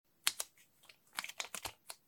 【環境音シリーズ】自動販売機
今回は、どこにでもある自動販売機で収録しました。
交通量の少ない時間帯に収録しました。
TASCAM(タスカム) DR-07Xのステレオオーディオレコーダー使用しています。